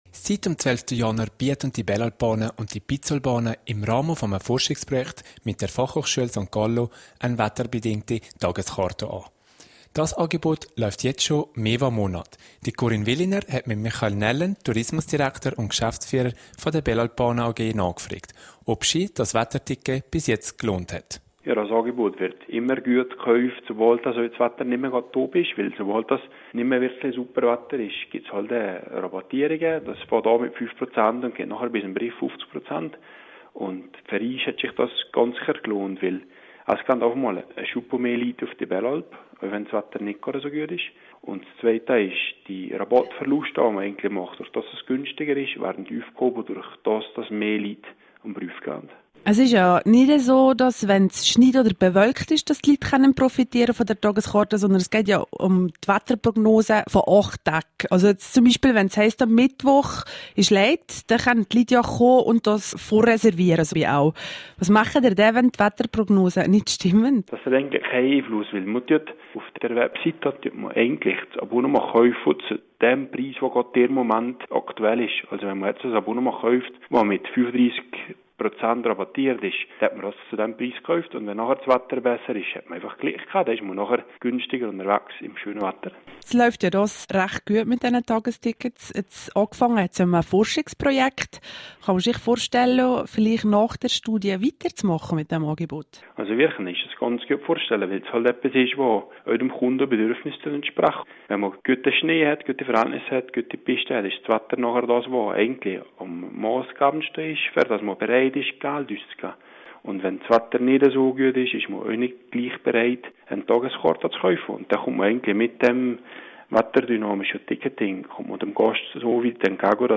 bg Interview zu den neuen Wettertickets der Belalp Bahnen (Quelle: rro)